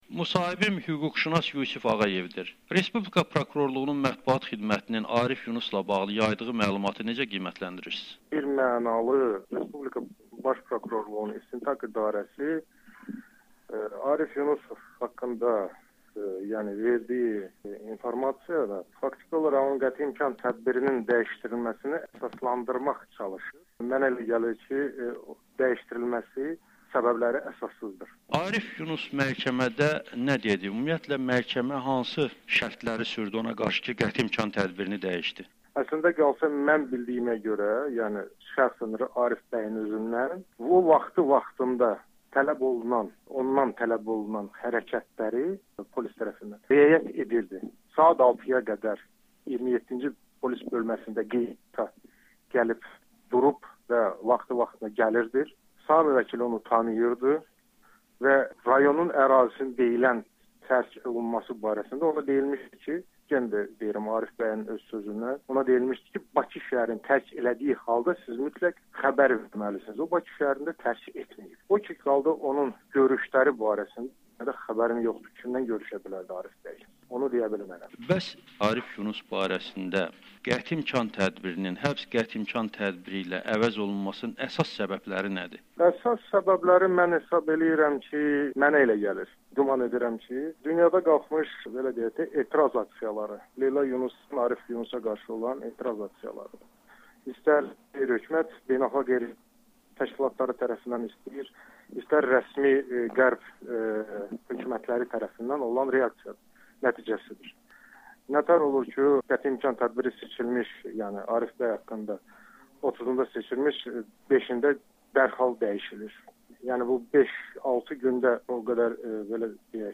[Audio-müsahibə]